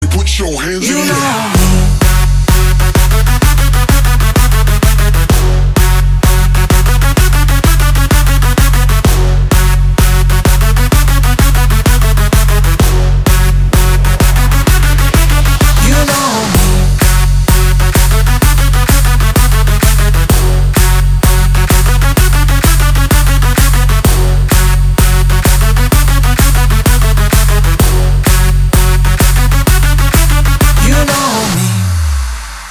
• Качество: 320, Stereo
ритмичные
мужской вокал
громкие
EDM
Big Room
динамичные